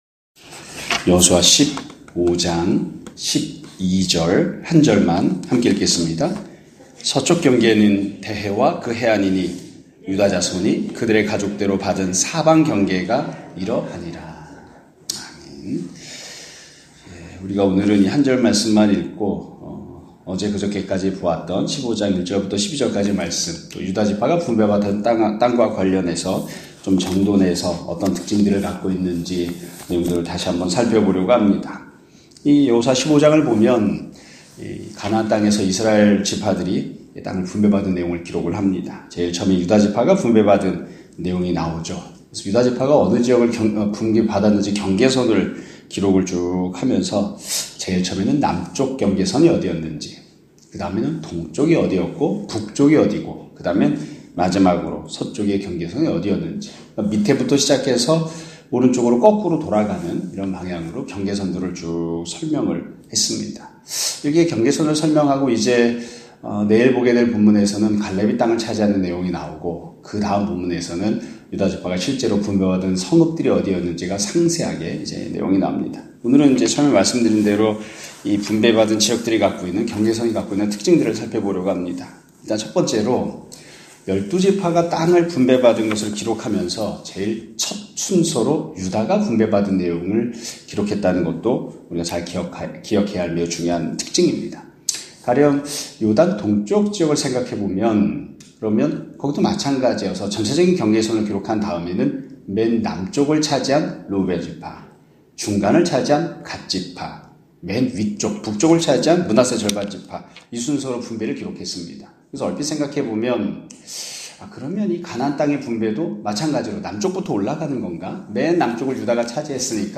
2024년 12월 4일(수요일) <아침예배> 설교입니다.